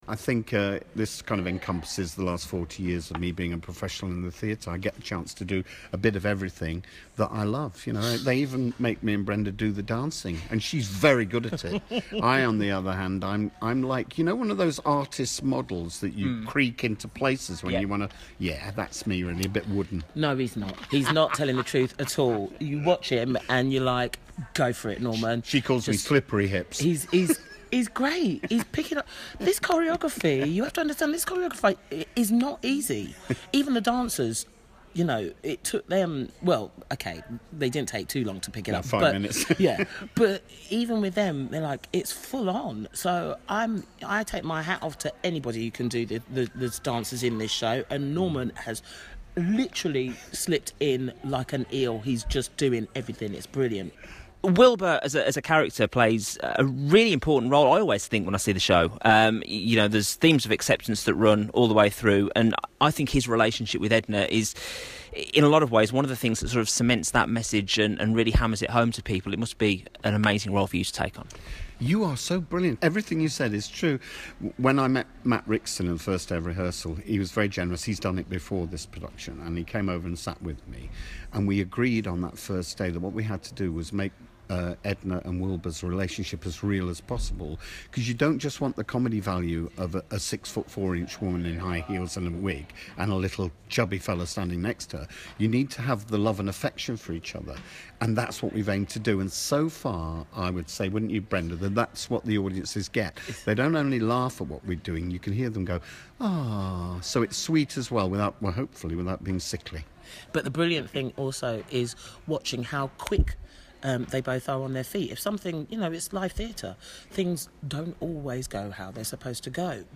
Brenda and Norman play Motormouth Maybelle and Wilbur Turnblad in the new UK tour of Hairspray. I caught up with them at the Millennium Centre in Cardiff.